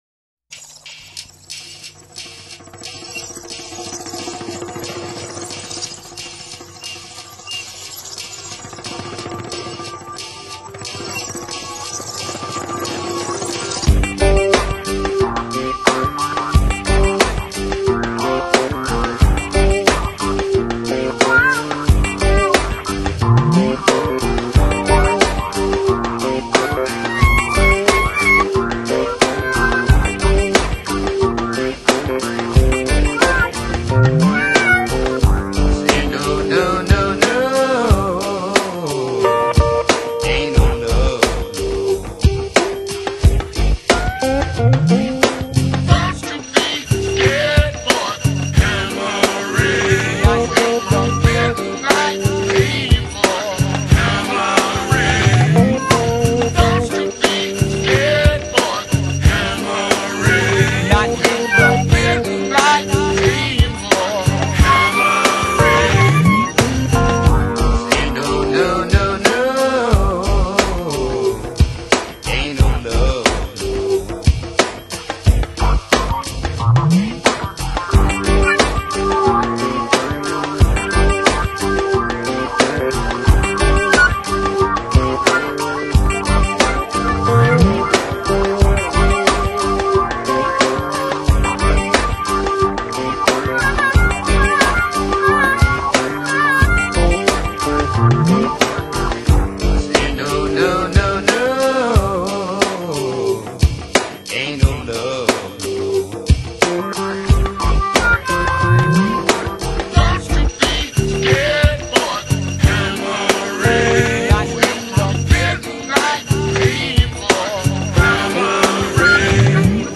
专辑特色： 时尚蓝色驰放沙发音乐